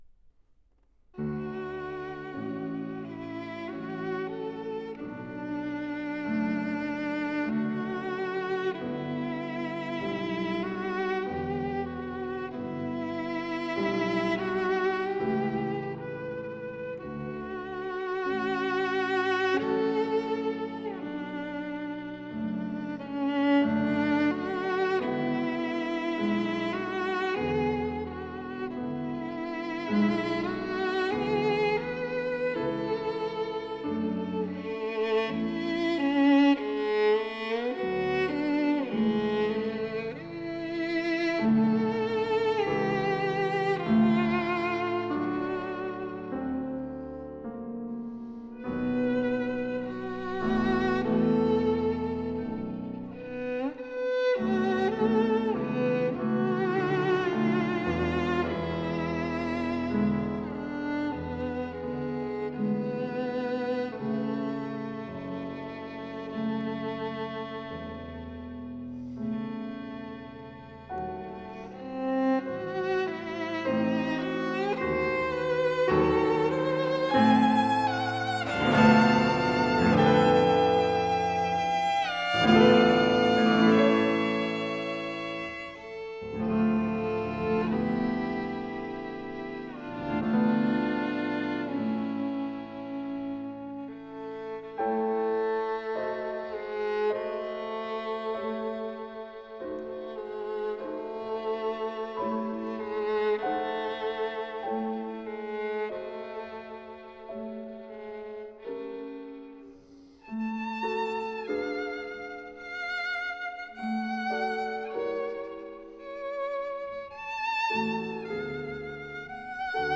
Sonata for violin & piano No. 1 in G major